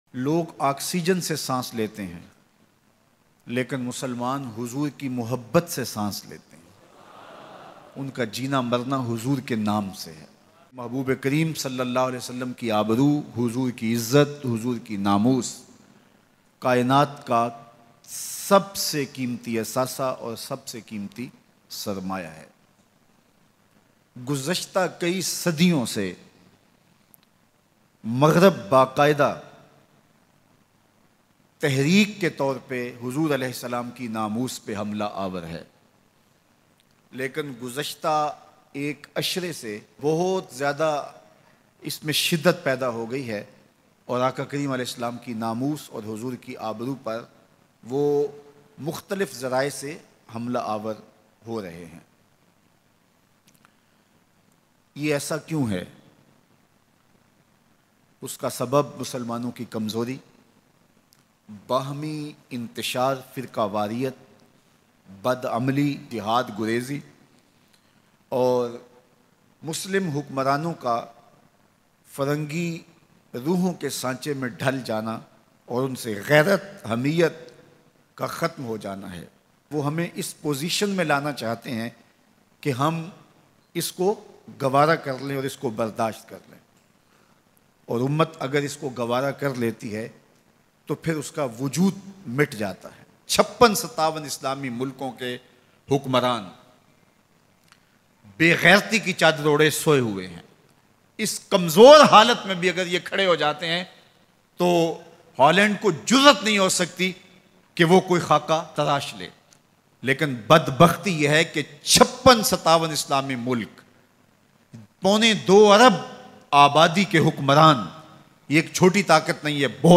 Gustakhana Khako k Hawale se Bayan MP3